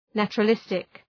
Προφορά
{,nætʃərə’lıstık}